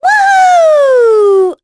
Talisha-Vox_Happy4.wav